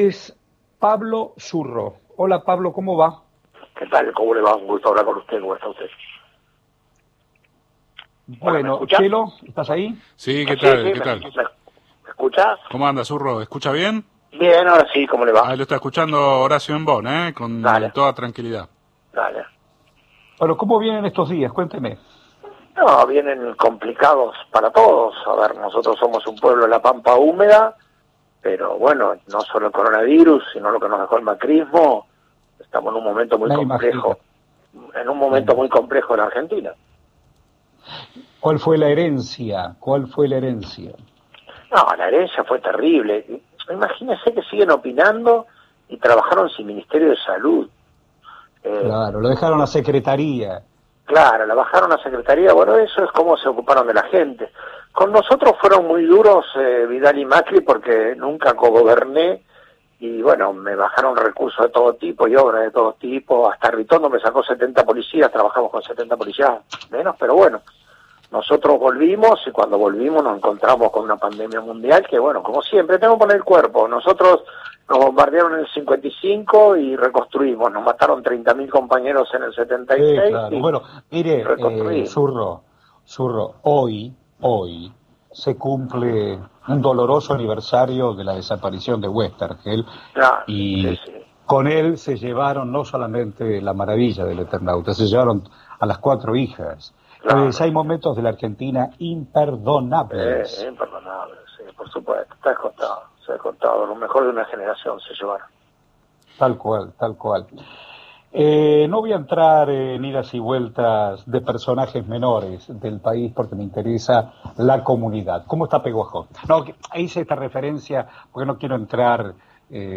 Entrevista a Pablo Zurro embón en nacional